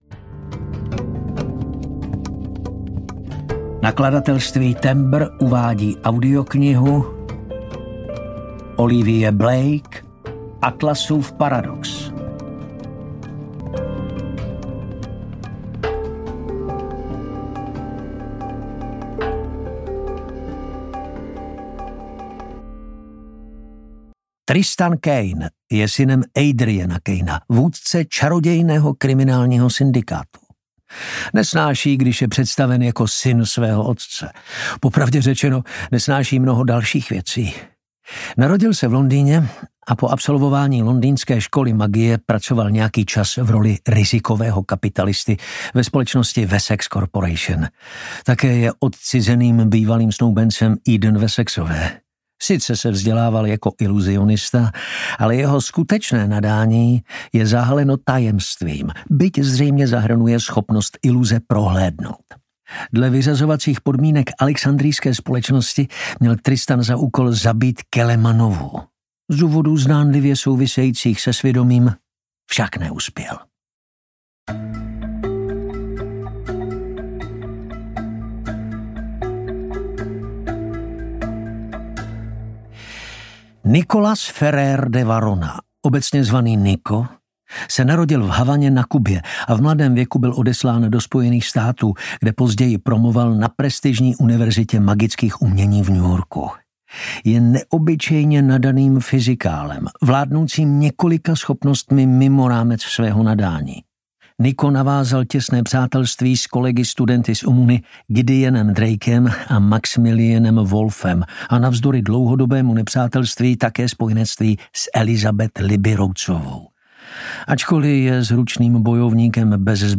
Atlasův paradox audiokniha
Ukázka z knihy